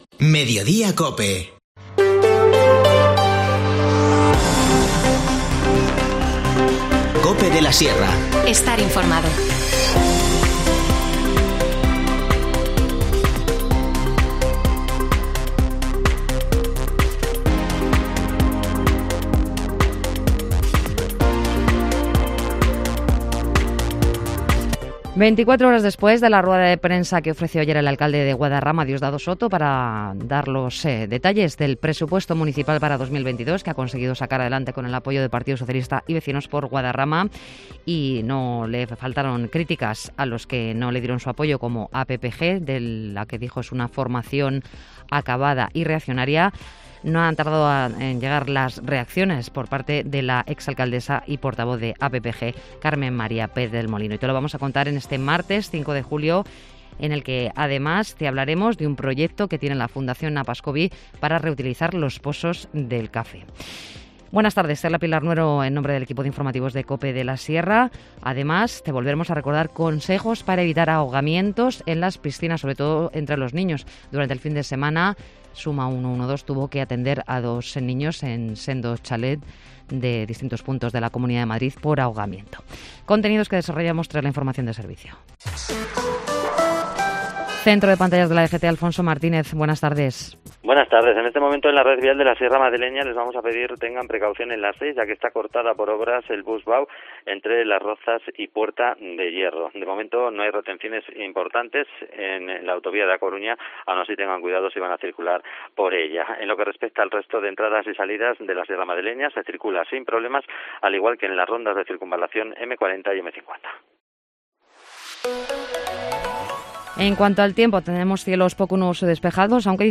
Informativo Mediodía 5 julio